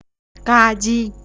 Velar
Ga-gyi  {Ga.kri:}
In the above, you will hear both the male and the female speaker pronouncing {kri:} as {gyi:}.